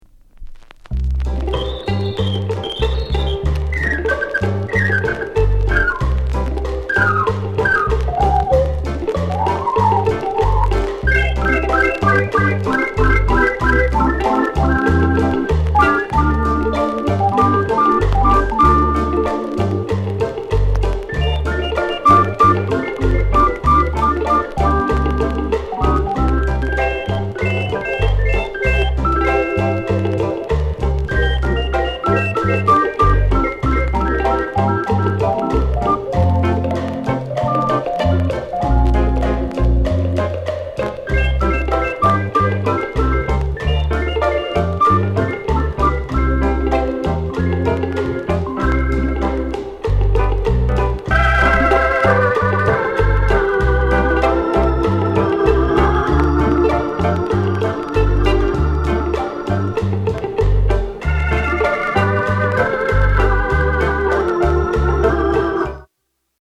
ROCKSTEADY INST